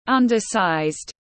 Nhỏ hơn cỡ bình thường tiếng anh gọi là undersized, phiên âm tiếng anh đọc là /ˌʌn.dəˈsaɪzd/ .
Undersized /ˌʌn.dəˈsaɪzd/